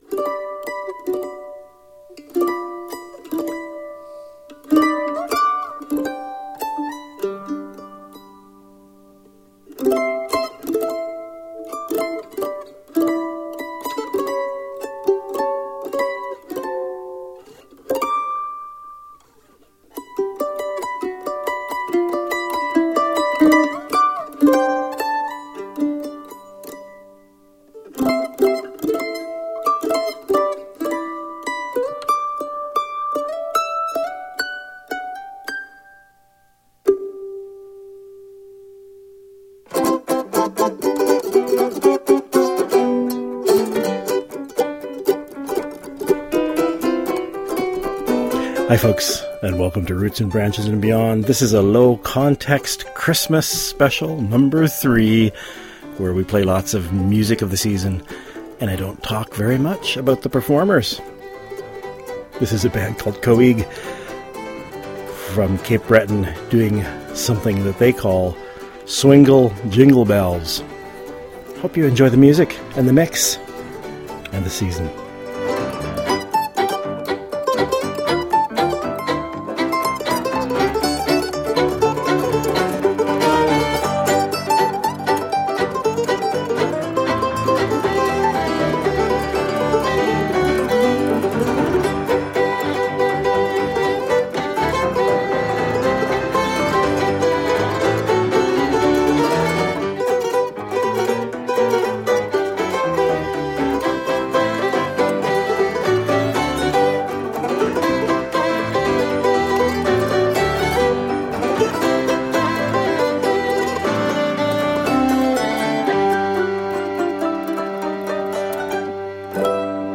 Lots of music, and less talk than usual, show # 3